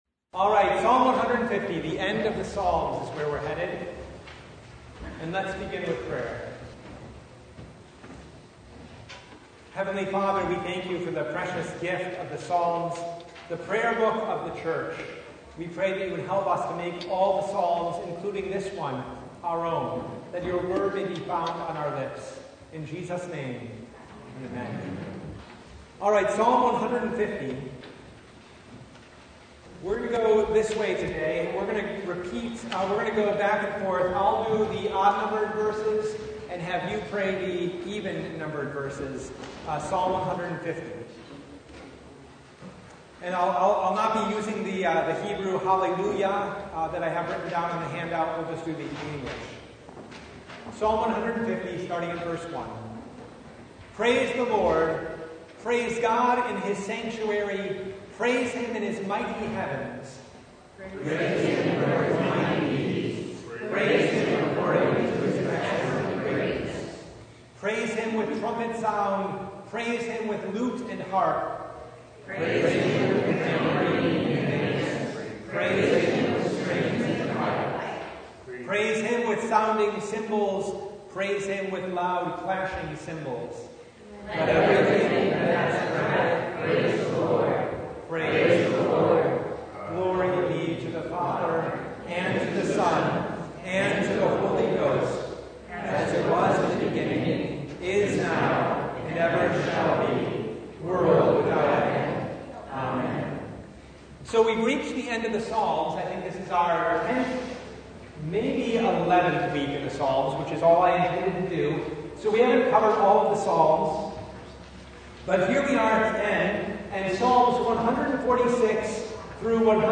Psalm 150 Service Type: Bible Study Praise the Lord!